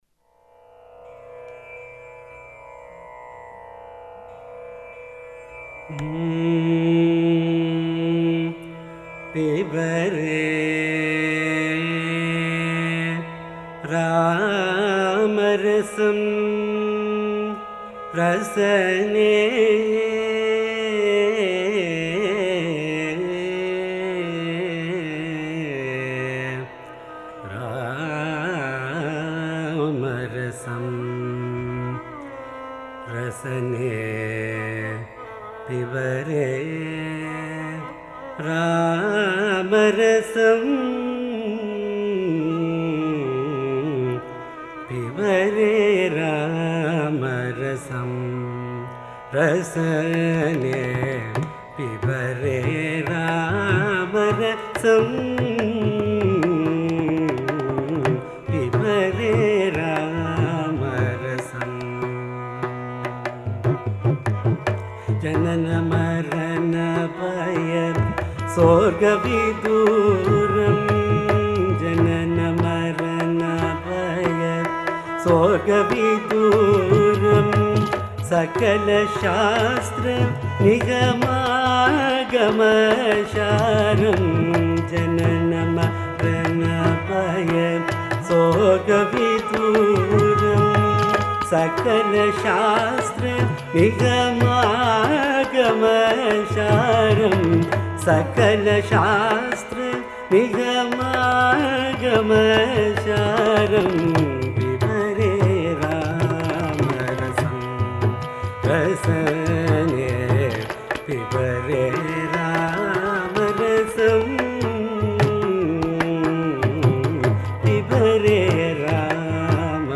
Song – Pibere RAmarasam Raga-Ahir Bhairavi Adi-Talam (8 beats cycle) Composer Sadasiva Brahmendra